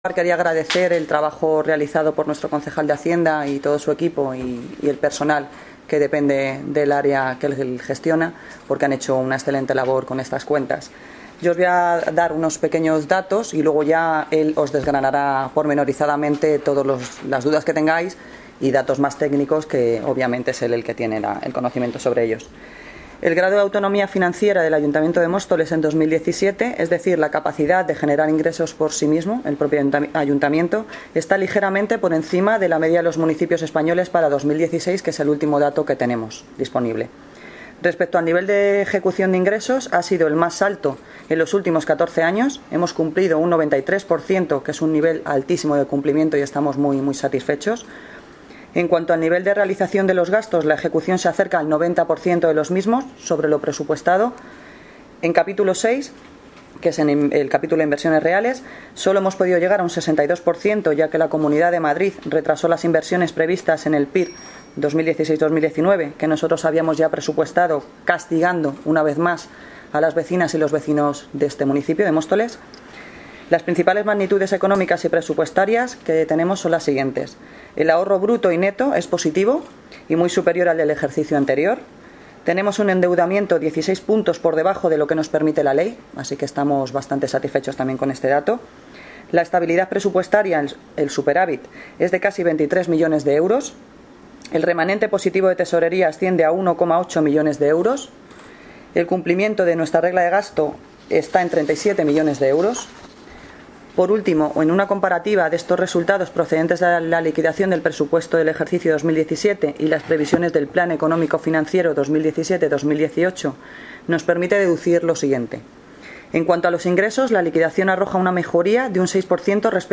Audio - Noelia Posse (Alcaldesa de Móstoles) Sobre liquidación ejercicio económico 2017